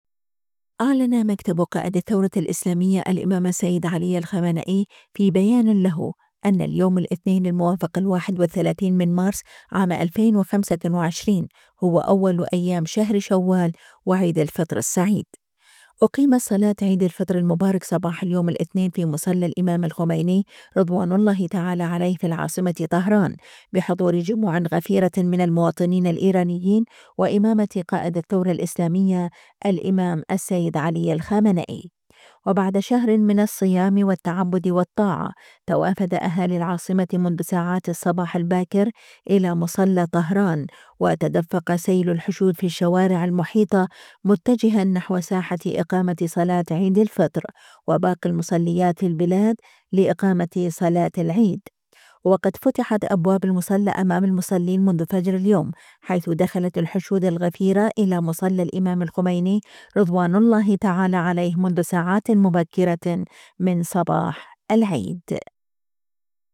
في مصلى الإمام الخميني (رض)..
أقيمت صلاة عيد الفطر المبارك، صباح اليوم الإثنين، في مصلى الإمام الخميني (رض) في العاصمة طهران، بحضور جموع غفيرة من المواطنين الإيرانيين وإمامة قائد الثورة الإسلامية الإمام السيد علي الخامنئي.